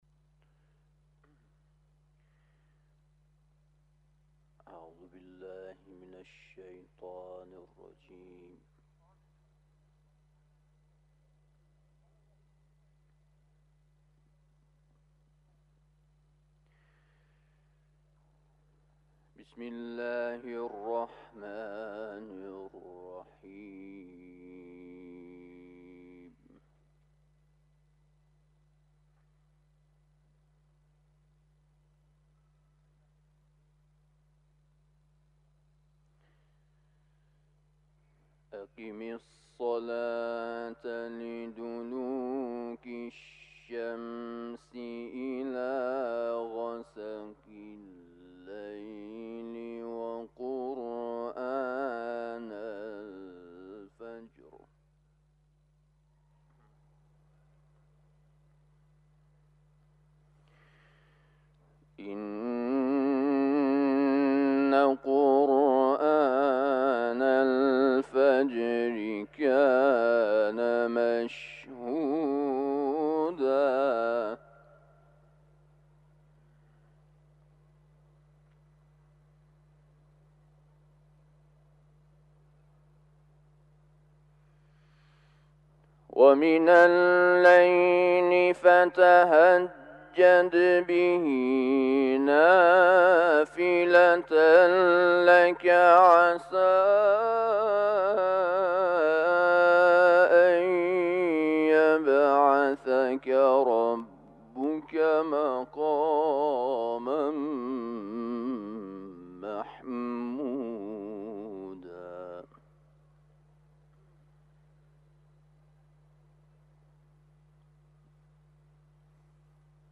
، آیات 78 تا 84 سوره «اسراء» را در جوار بارگاه منور رضوی تلاوت کرده است.
تلاوت